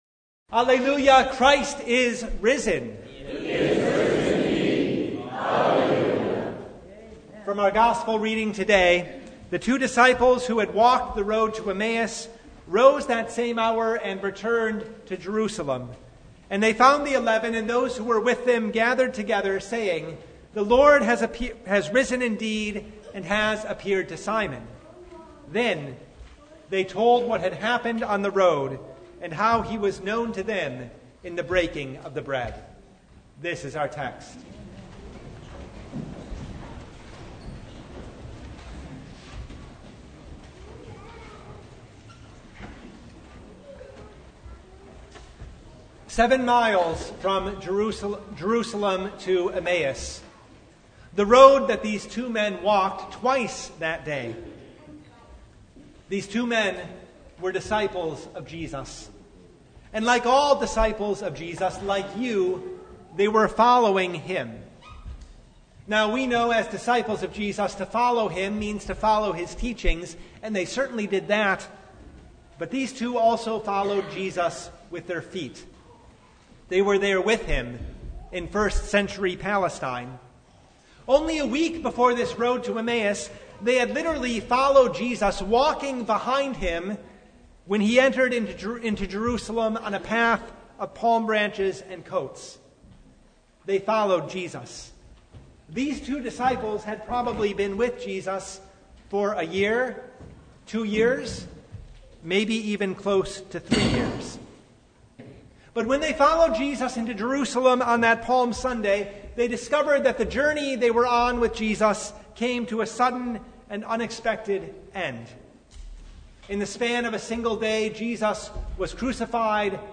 Luke 24:13-35 Service Type: Sunday The two disciples on the road to Emmaus were disappointed .